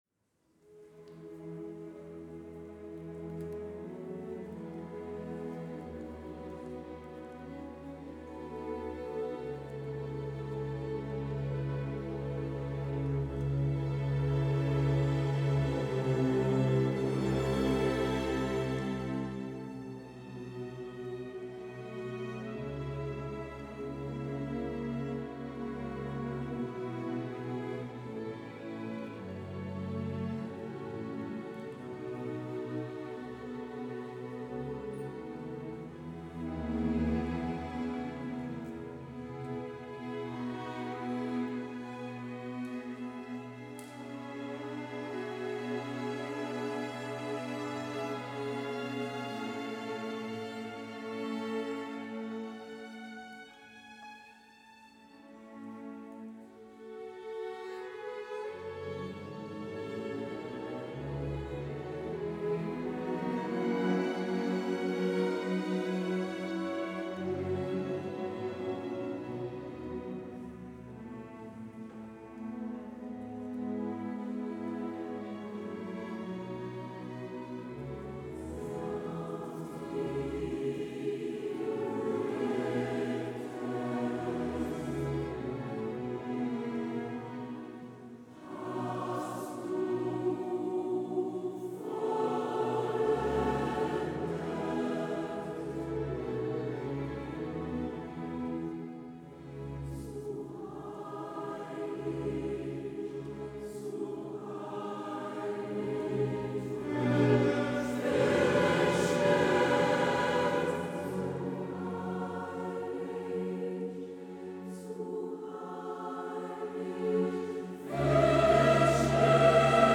Elegischer Gesang de Beethoven – Choeur & Orchestre Universitaire Régional de Caen Normandie
Enregistrement public 19 mars 2017 à l’Abbaye aux dames
Elegischer Gesang est une courte pièce de Ludwig van Beethoven écrite pour quatuor à cordes et quatre voix mixtes.